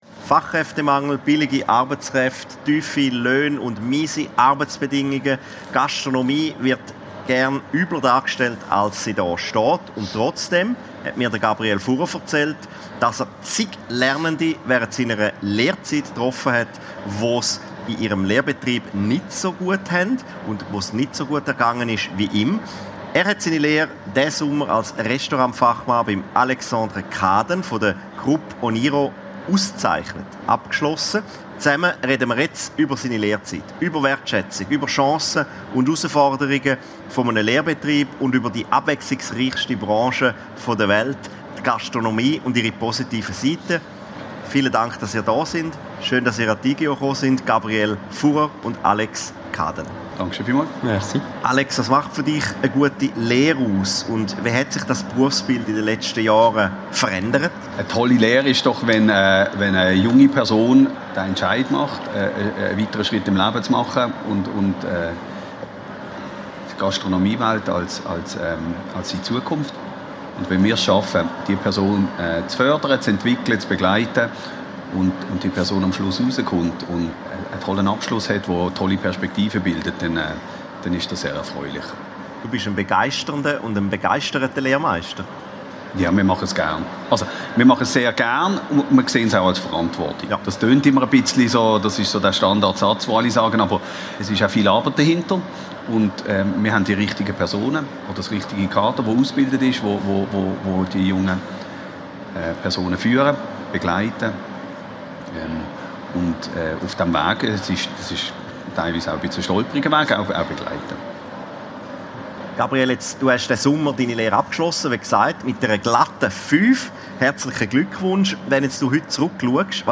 Das Podiumsgespräch wurde am 19. November 2025 auf der Cheminée-Chats-Bühne der Igeho 2025 aufgezeichnet.